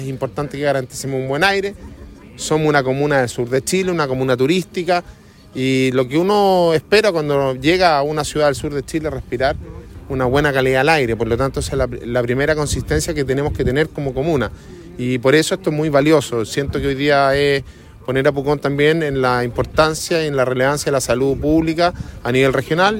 El alcalde de Pucón, Sebastián Álvarez, dijo que es importante garantizar una buena calidad del aire.